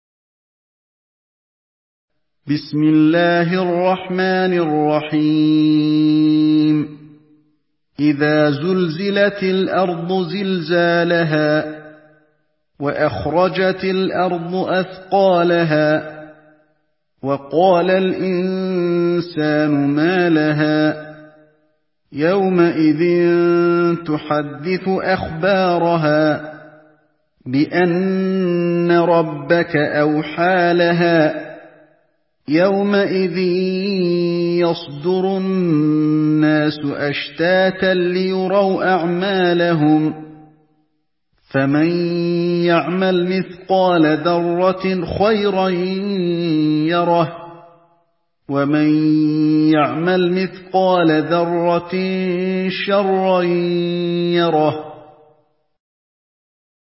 Surah الزلزله MP3 in the Voice of علي الحذيفي in حفص Narration
مرتل